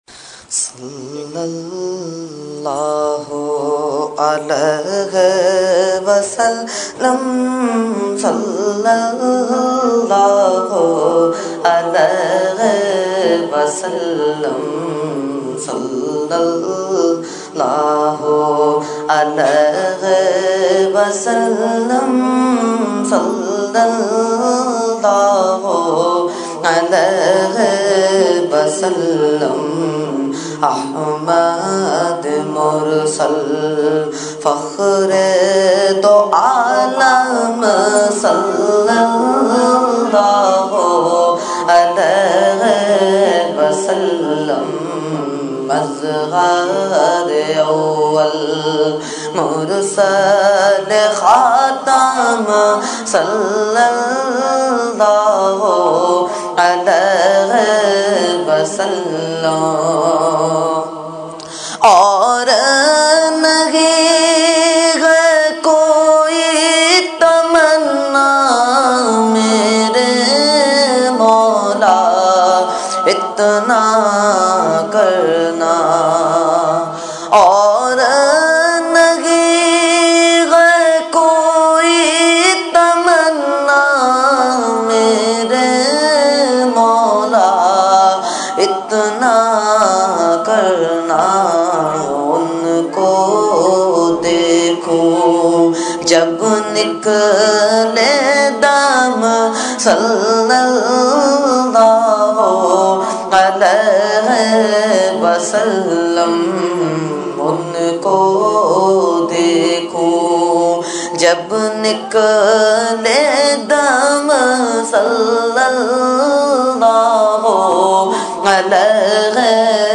Category : Naat | Language : UrduEvent : Mehfil Milad 1 July 2012 C-Area Liaqatabad